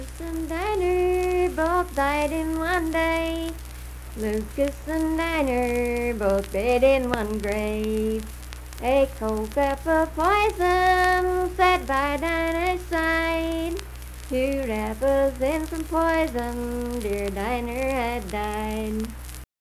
Unaccompanied vocal music
Verse-refrain 1(4). Performed in Strange Creek, Braxton, WV.
Voice (sung)